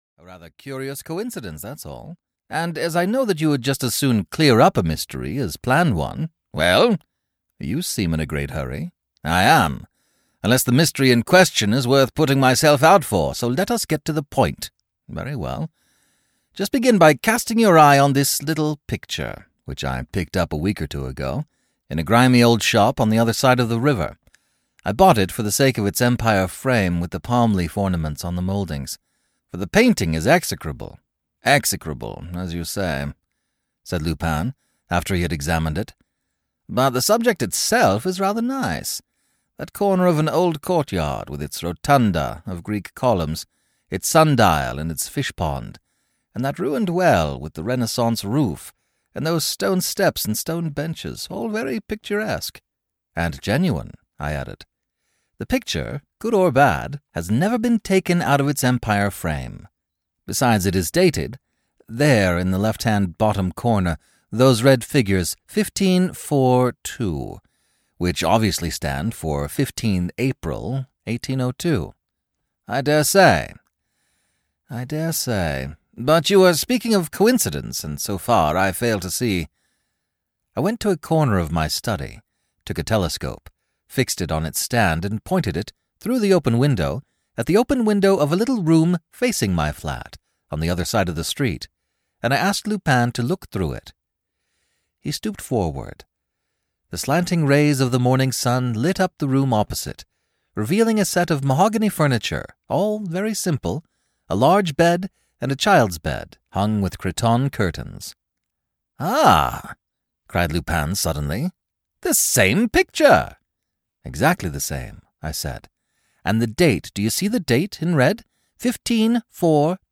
Ukázka z knihy
He masterfully breathes life back into literary classics and plays with a wide array of voices and accents and has produced over 500 audiobooks.